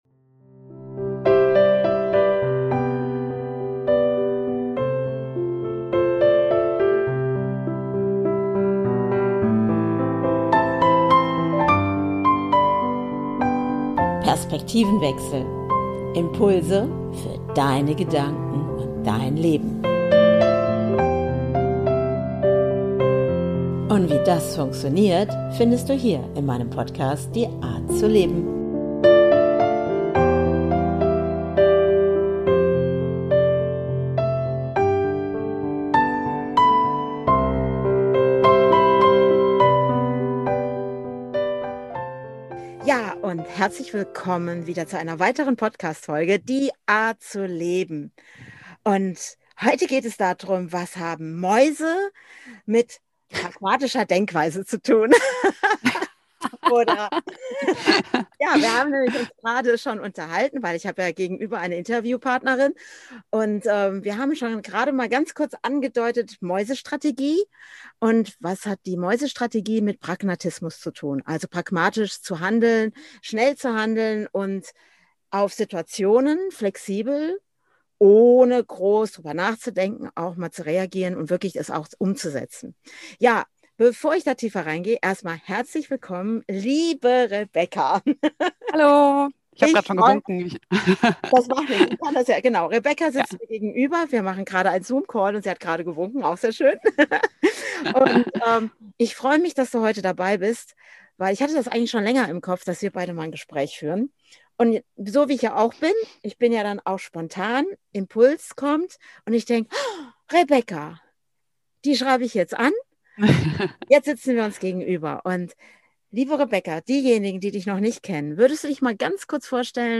#69 Was hat Pragmatismus mit Mäusen zu tun? Interview